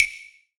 SOUTHSIDE_percussion_sticks.wav